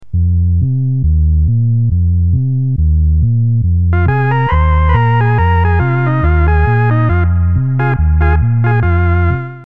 Demo 9: String 16', trumpet 8' and flute 4' with lots of reverb, showing the cool sound of the Accutronics-style spring reverb of the F/AR unit